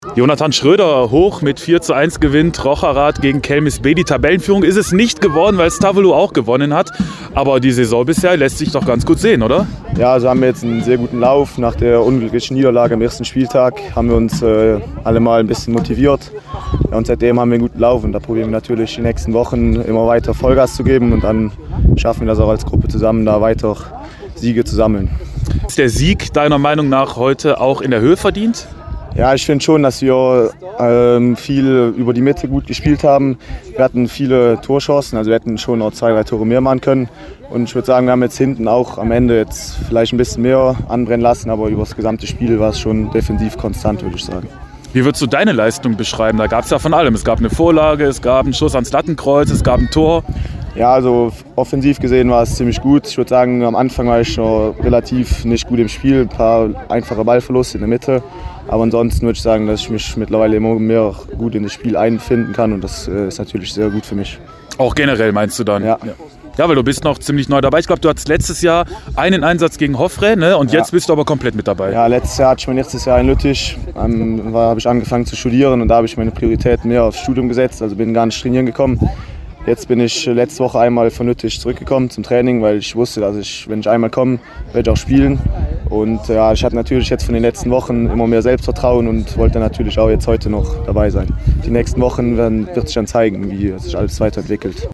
sprach nach dem Spiel mit Rocheraths Nachwuchskicker